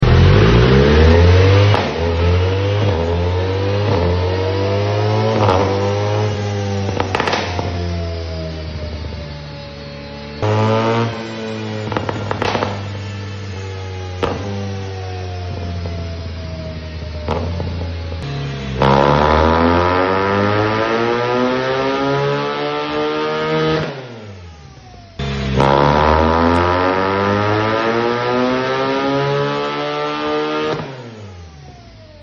🇩🇪 BMW R1300GS with Full Titanium Akrapovic Exhaust came for a tuning after modifications! Stock measurements: 142 Hp After tune measurements: 152Hp Light crackle on decel requested by the customer👌🏼 Better performance.